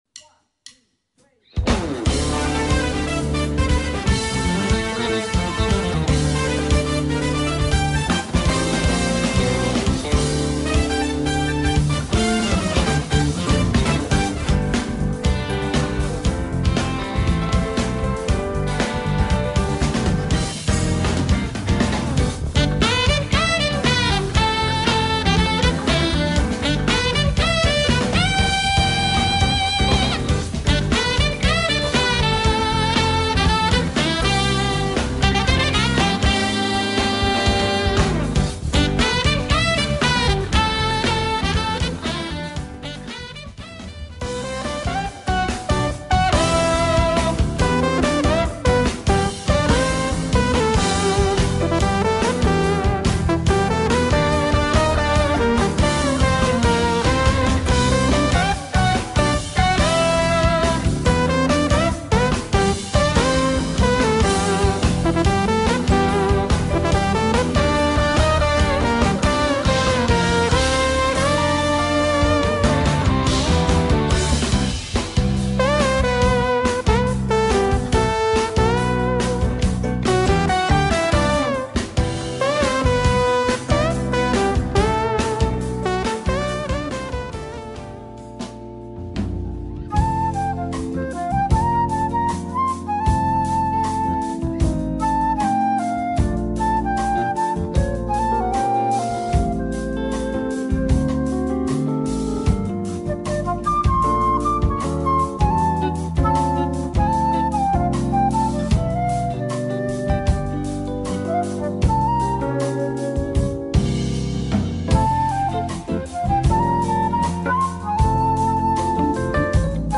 Sax
Guitar
Keyboard
Bass
Drums